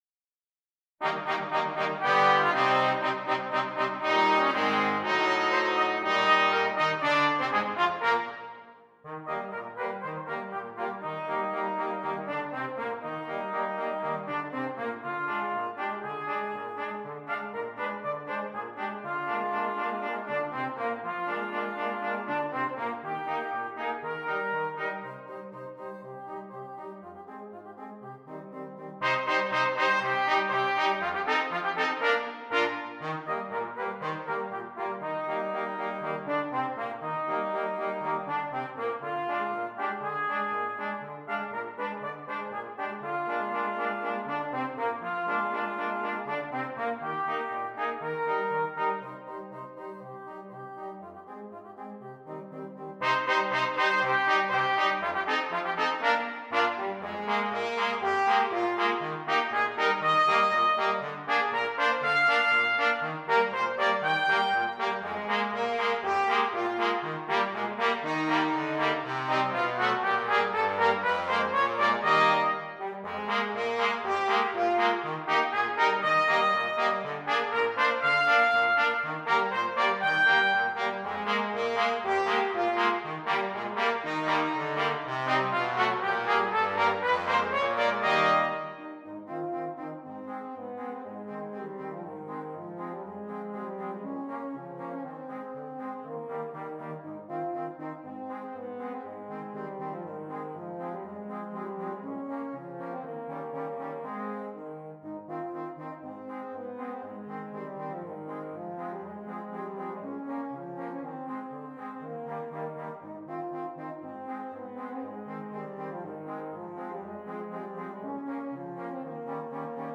Brass Quartet
march for brass quartet